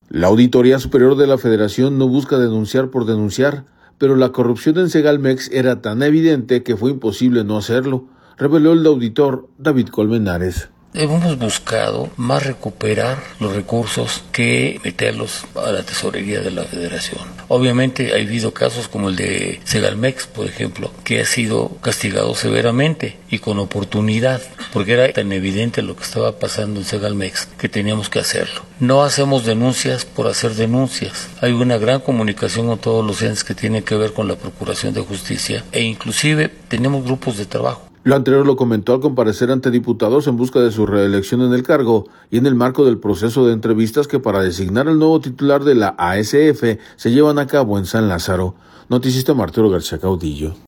Lo anterior lo comentó al comparecer ante diputados en busca de su reelección en el cargo y en el marco del proceso de entrevistas que, para designar al nuevo titular de la ASF, se llevan a cabo en San Lázaro.